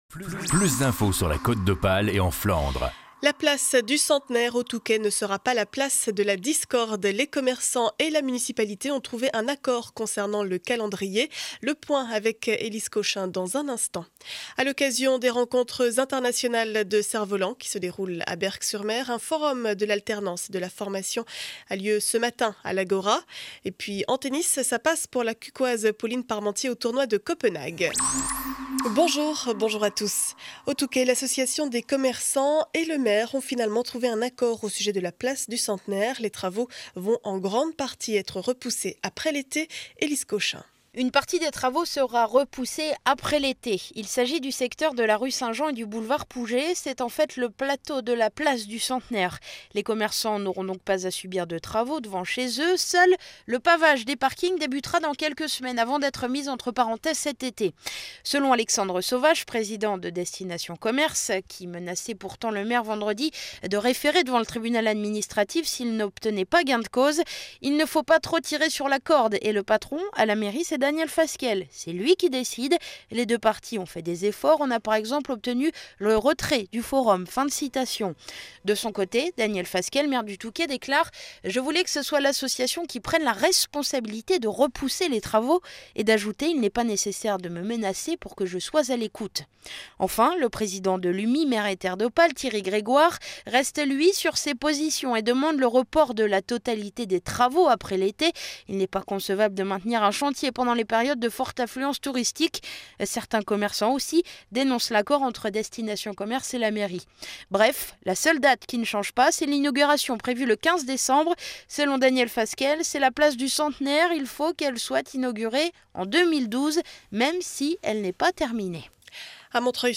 Journal du mercredi 11 avril 2012 7 heures 30 édition du Montreuillois.